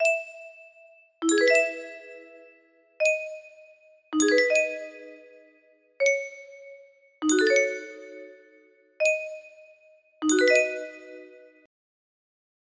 Test music box melody